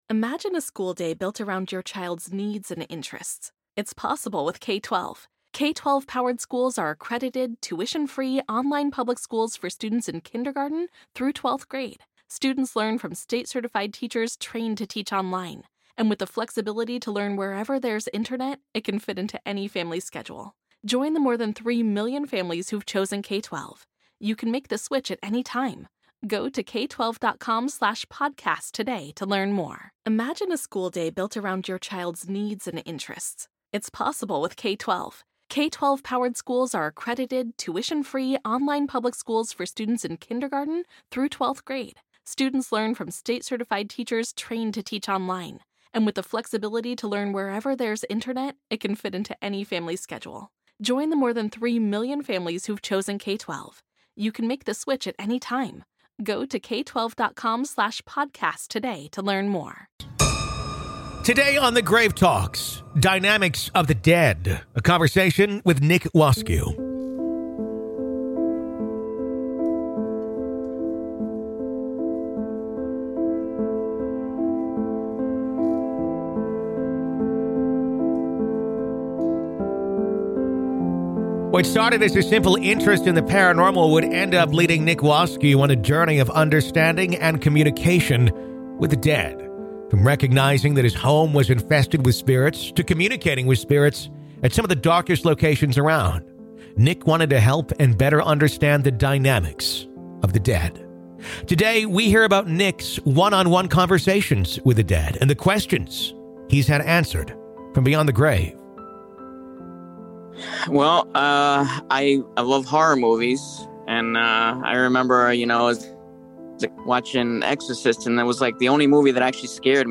In Part One of this compelling Grave Talks interview